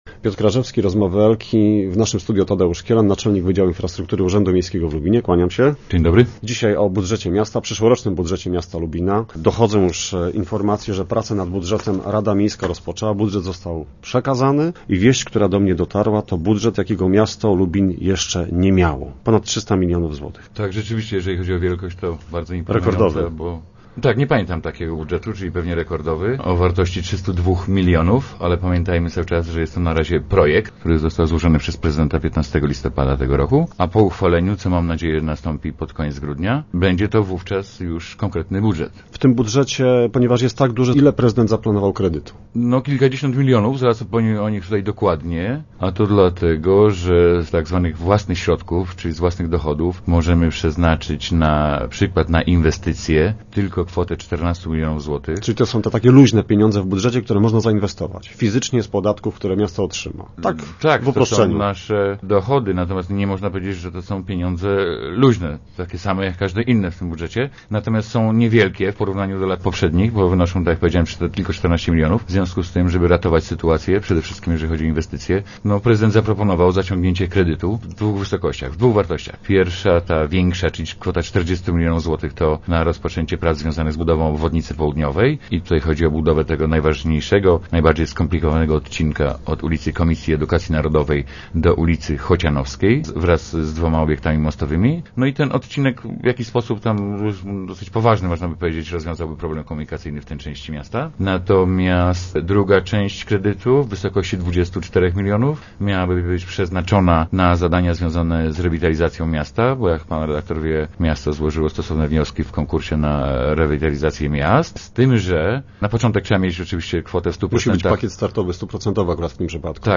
Kiedy ruszy budowa obwodnicy południowej, która będzie najdroższą inwestycją w historii Lubina. O tym była mowa w Rozmowach Elki.